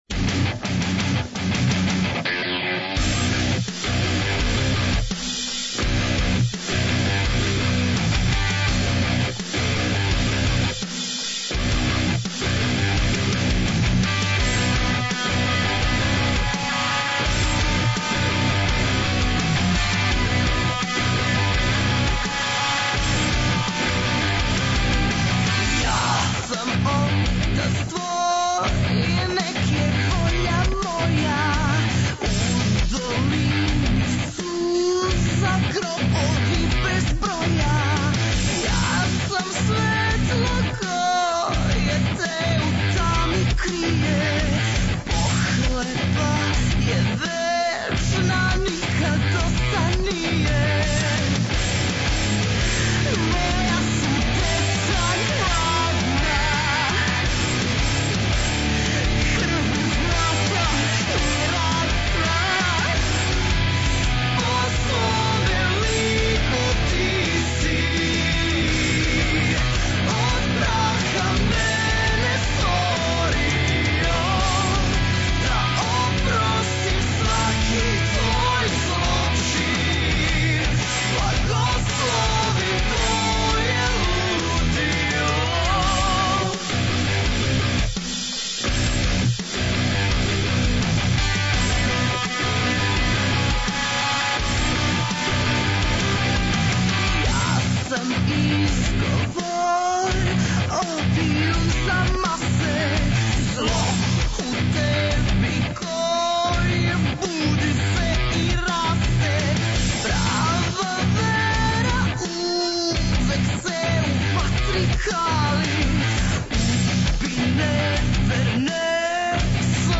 Ноћас са нама горштаци из Ужица, група 'The Highlanders'!